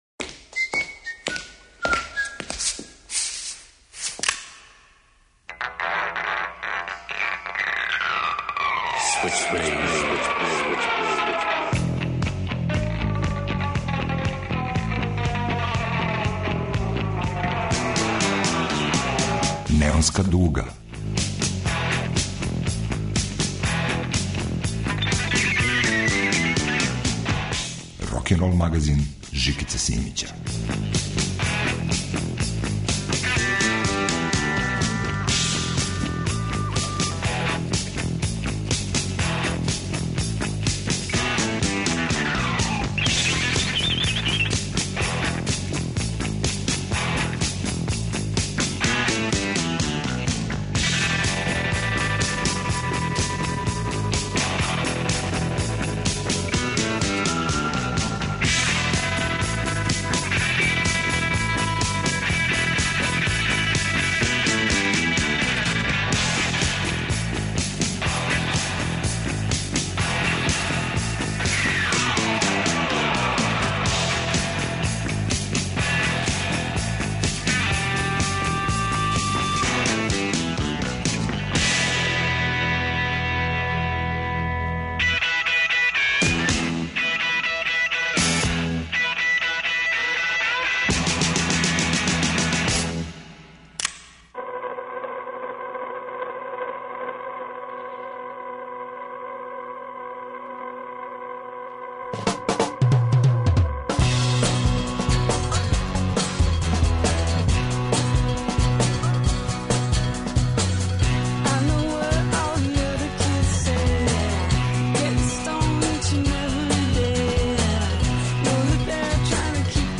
Ура за отпаднике! Од гаражног попа до ангажованог рока.
Рокенрол као музички скор за живот на дивљој страни. Вратоломни сурф кроз време и жанрове.